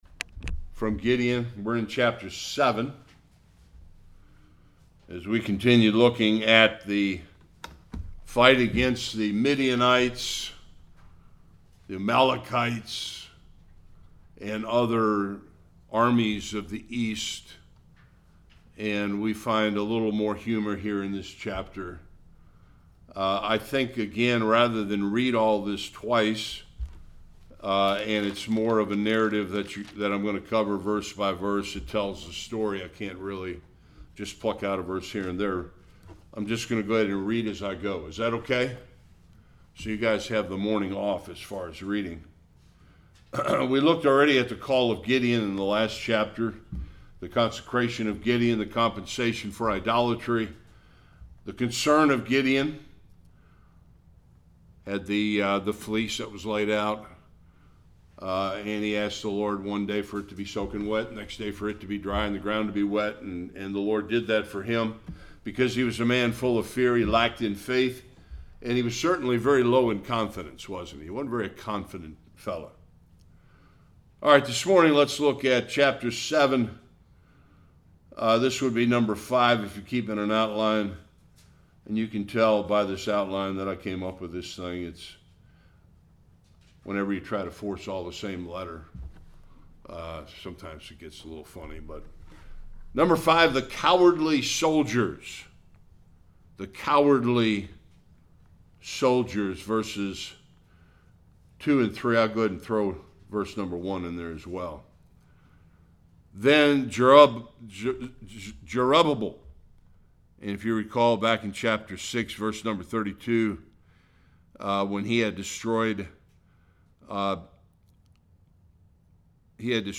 Judges 7:1- 8:9 Service Type: Sunday School God uses Gideon and 300 men to defeat the massive Midianite army.